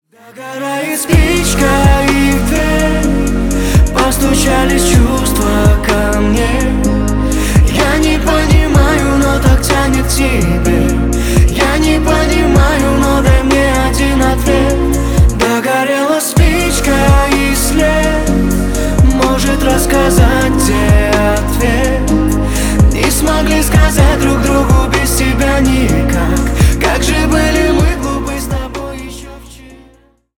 Поп Музыка
спокойные